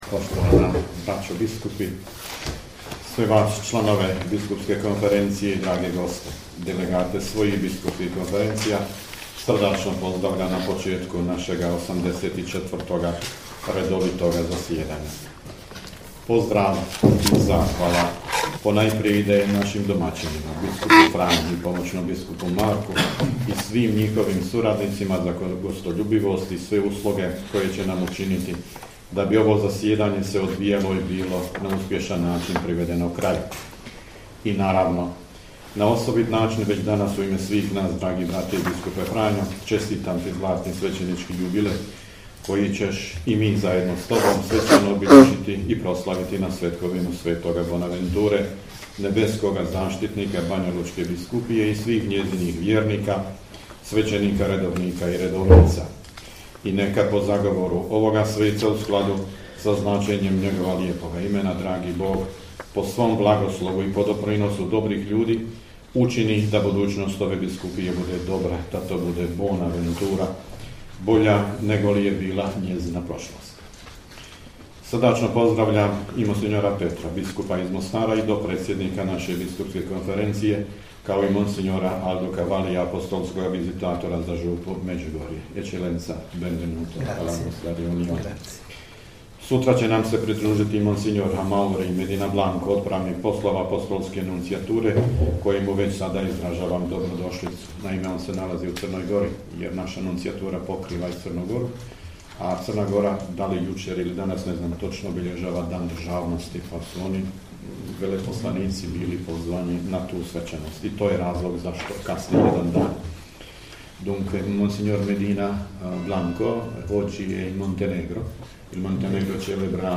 AUDIO: UVODNI POZDRAV NADBISKUPA VUKŠIĆA NA POČETKU 84. ZASJEDANJA BISKUPSKE KONFERENCIJE BOSNE I HERCEGOVINE
Na početku 84. redovitog zasjedanja Biskupske konferencije Bosne i Hercegovine, koje je započelo s radom u srijedu, 13. srpnja 2022. u prostorijama Biskupskog ordinarijata u Banjoj Luci, nadbiskup metropolit vrhbosanski i apostolski upravitelj Vojnog ordinarijata u BiH mons. Tomo Vukšić, predsjednik BK BiH, uputio je uvodni pozdrav koji prenosimo u cijelosti: